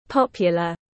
/ˈpɒpjələ(r)/
popular_UK.mp3